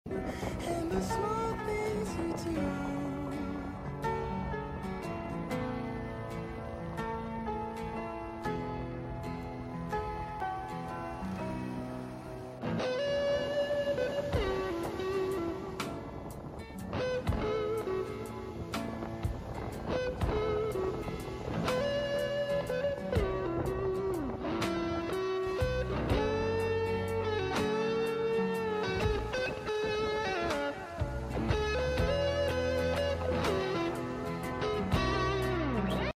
The sound of the sea